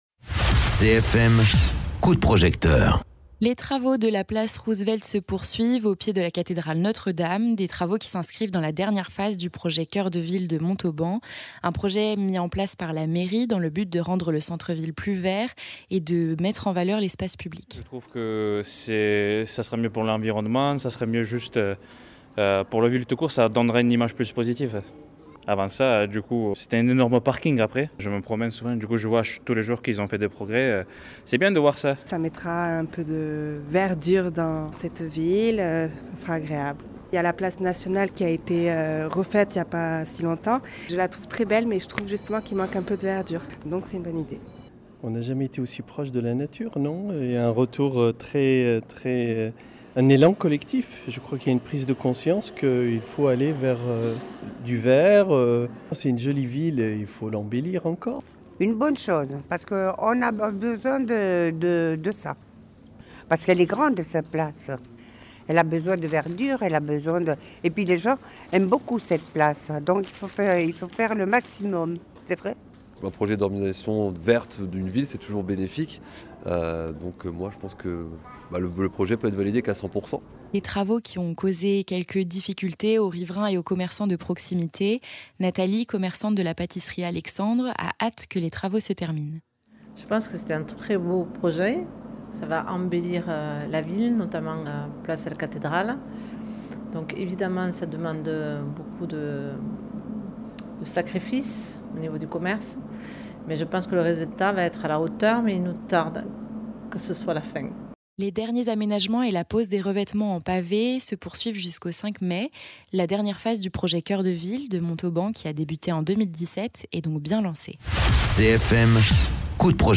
Interviews
Les habitants de la ville donnent leur avis sur les aménagements réalisés.